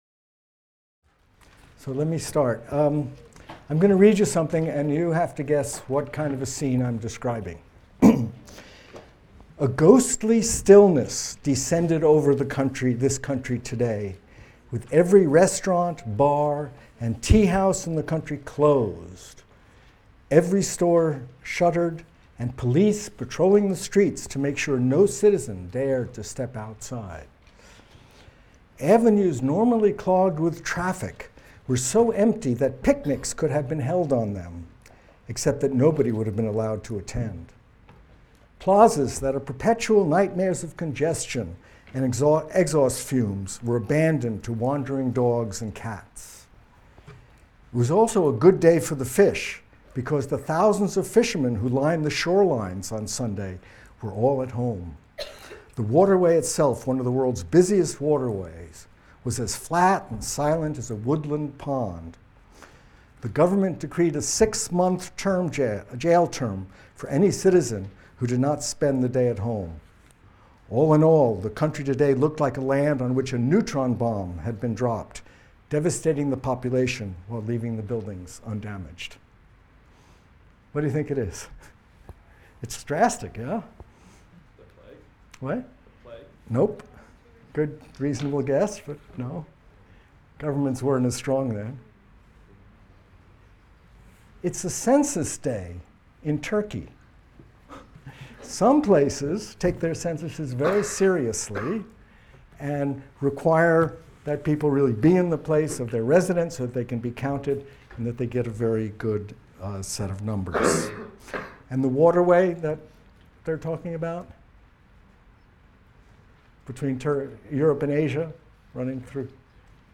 MCDB 150 - Lecture 10 - Quantitative Aspects | Open Yale Courses